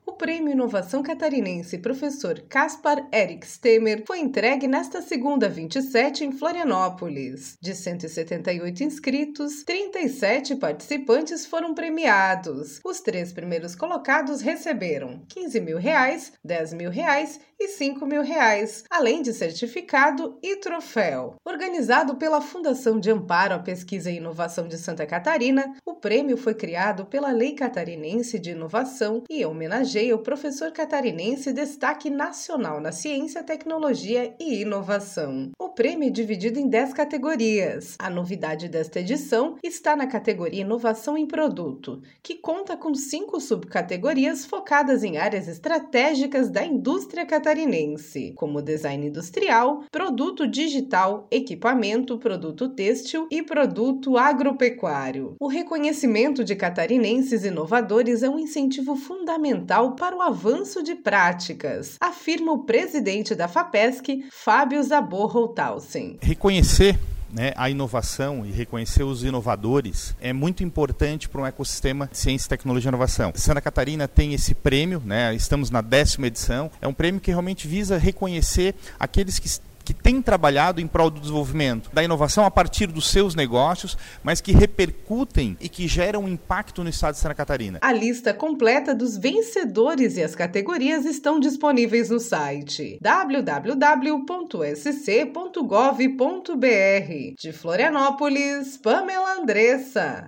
O reconhecimento de catarinenses inovadores é um incentivo fundamental para o avanço de práticas, afirma o presidente da Fapesc, Fábio Zabot Holthausen: